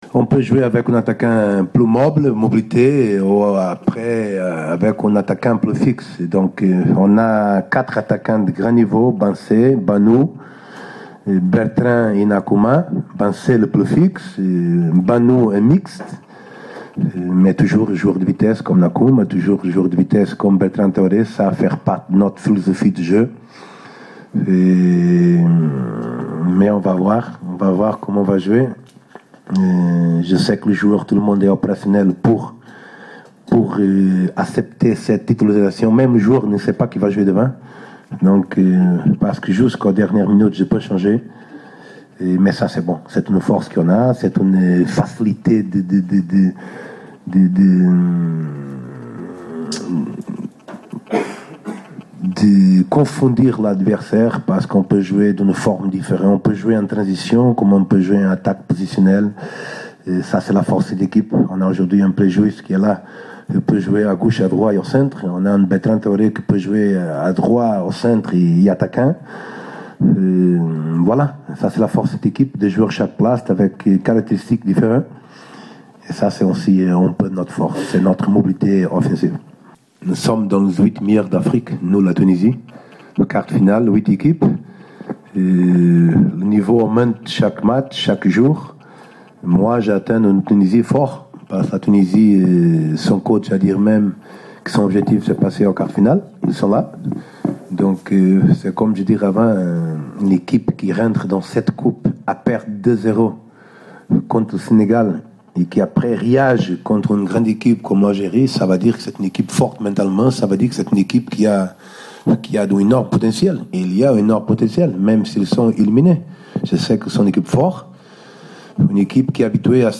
أكد مدرب بوركينا فاسو البرتغالي باولو دوارتي خلال الندوة الصحفية أنه قد أعد لاعبيه جيدا للمقابلة المرتقبة أمام المنتخب التونسي يوم غد السبت بملعب الصداقة بليبروفيل بالغابون في إطار الدور ربع النهائي لكأس إفريقيا 2017.